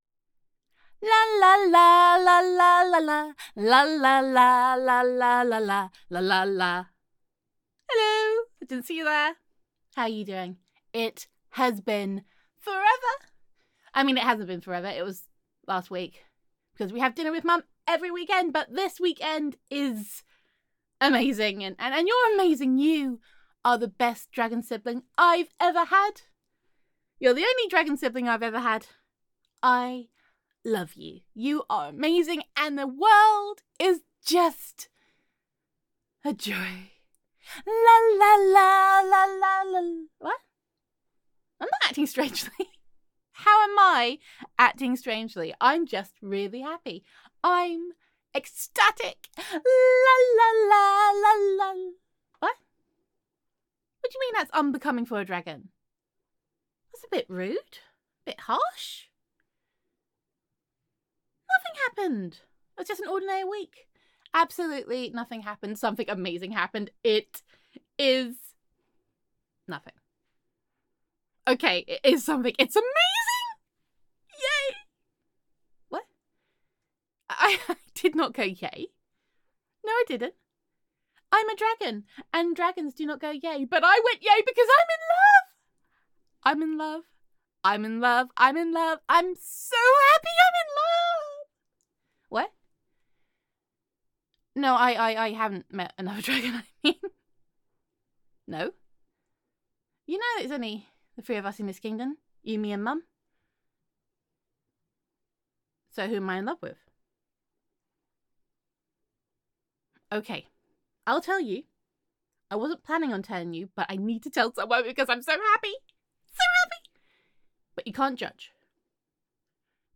[F4A] Unbecoming for a Dragon
[Dragon Roleplay]
[Happy Dragon Sounds]